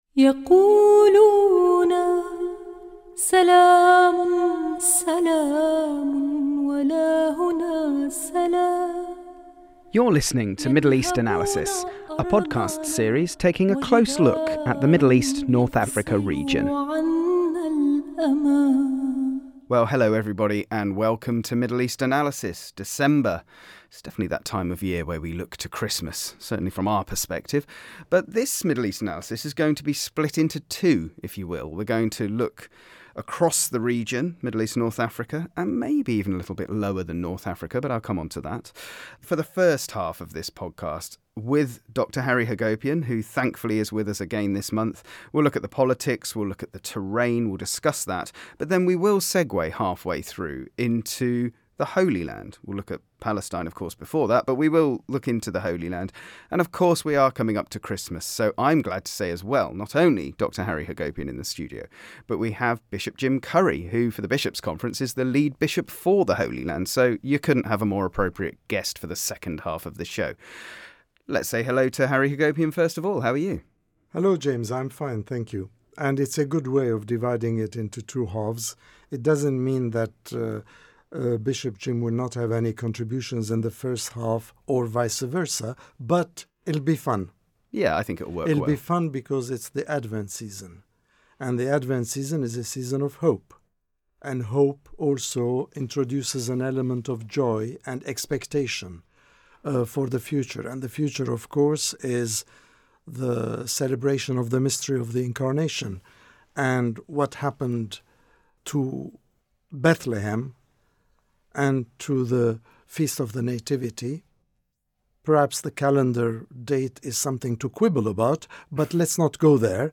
Our Lead Bishop for the Holy Land, Bishop Jim Curry, has appeared as a guest on the December 2025 episode of Middle East Analysis, a podcast series that examines the complex religious, political and socio-economic realities of the region covering the Middle East, North Africa and Gulf States.